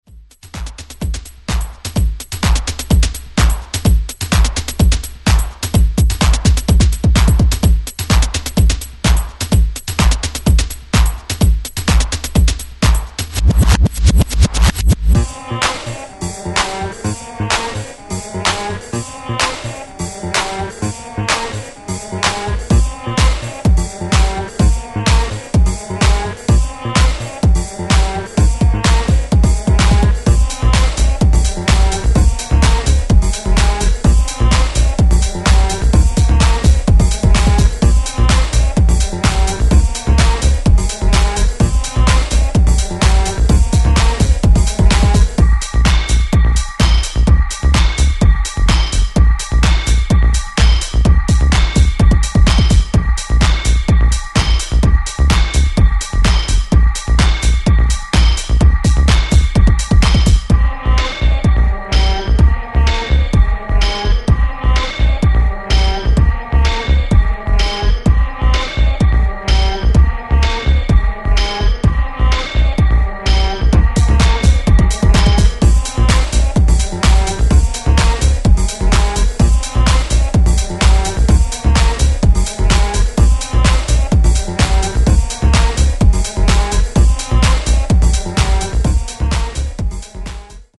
TECHNO/ELECTRO